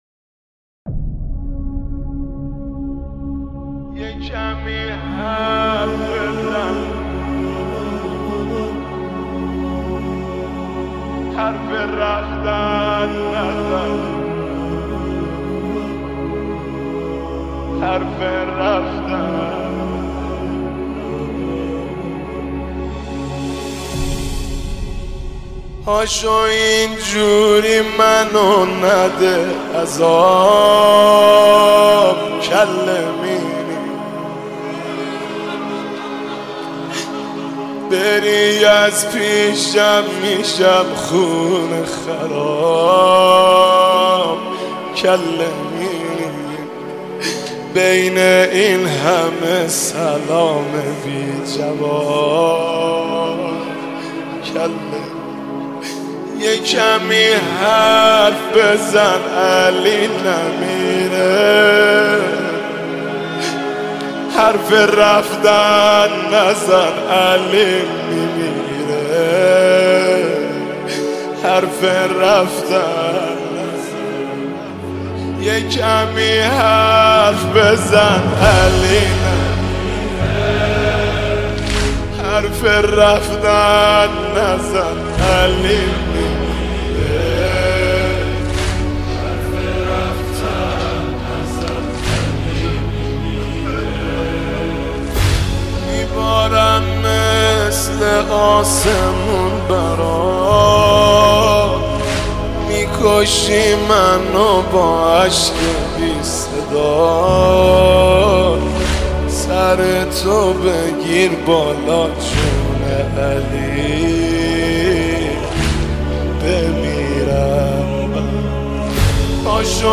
نوحه
مداحی